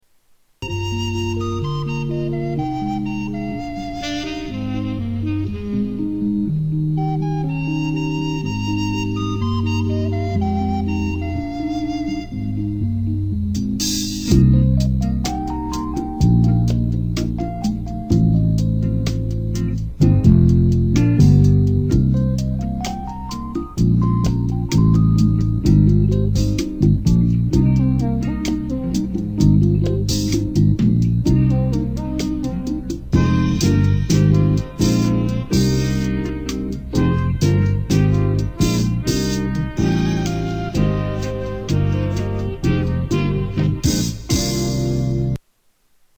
Category: Television   Right: Personal
Comedy